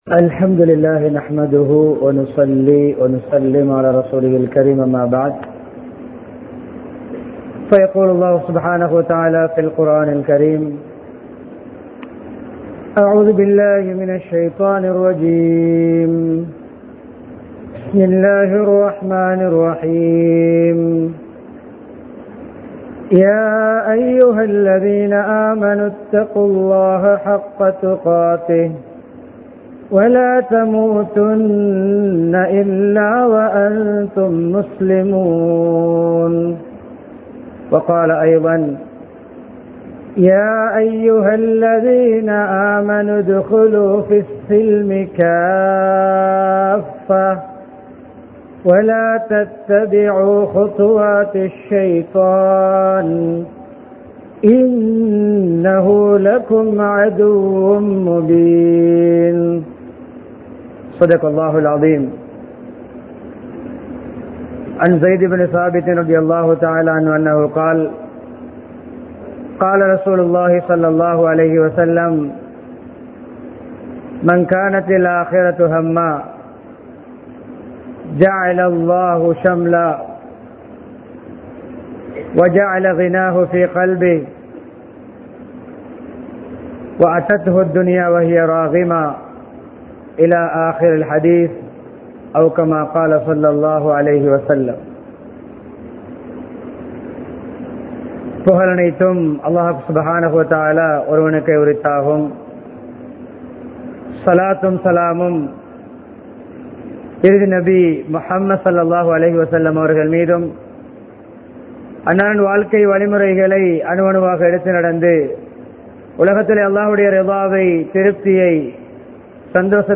ஆன்மீகத்தின் அவசியம் | Audio Bayans | All Ceylon Muslim Youth Community | Addalaichenai
Thissa, Kirinda Jumua Masjidh